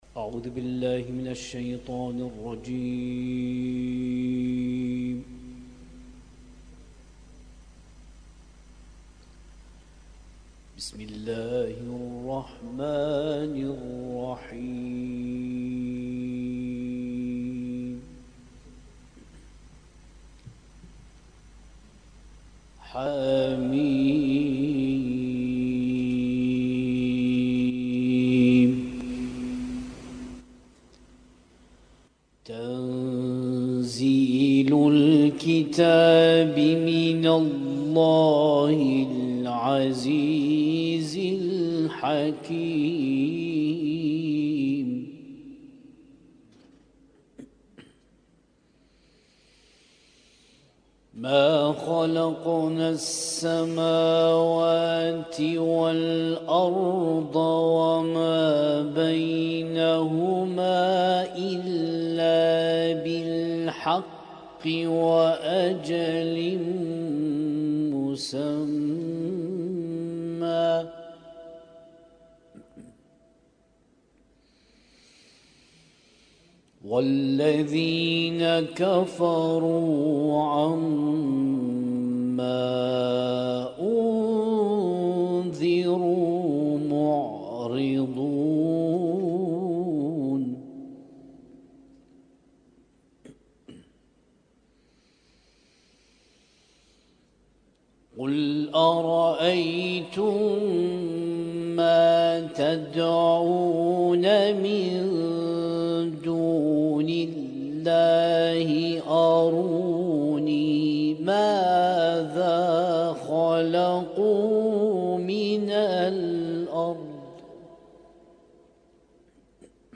اسم التصنيف: المـكتبة الصــوتيه >> القرآن الكريم >> القرآن الكريم - القراءات المتنوعة